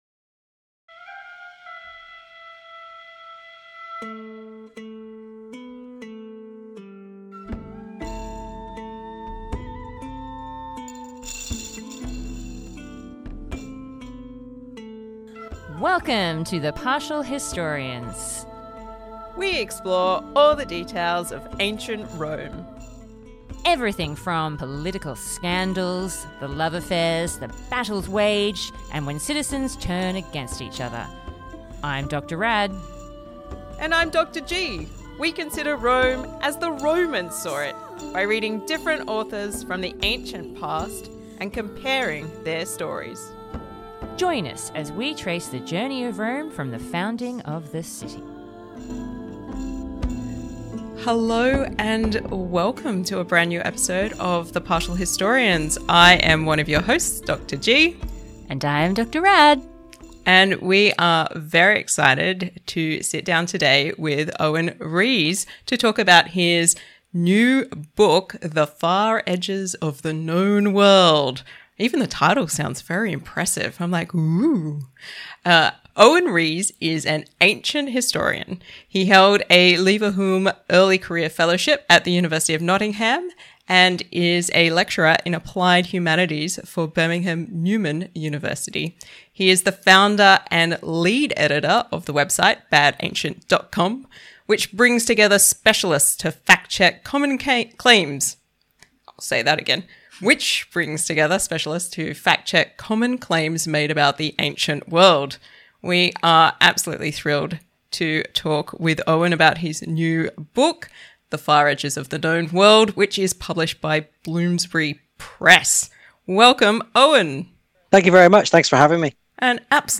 We are super excited for this conversation.